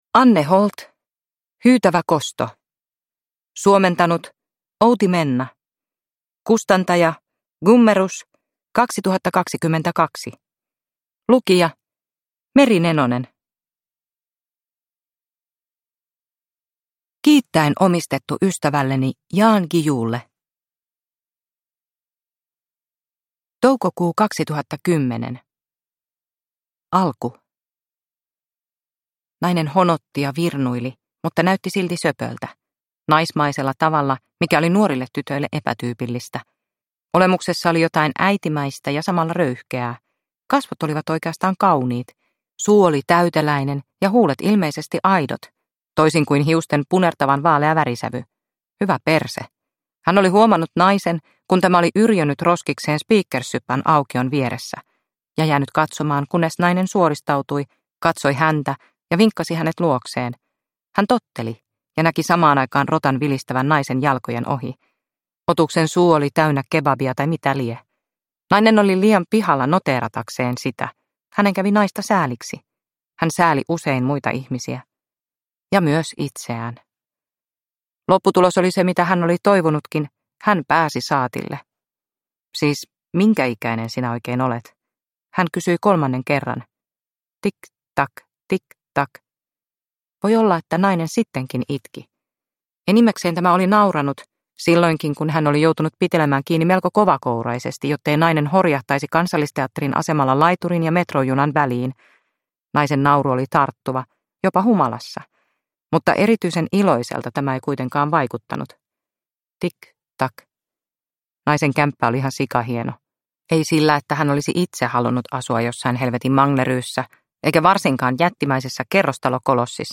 Hyytävä kosto – Ljudbok – Laddas ner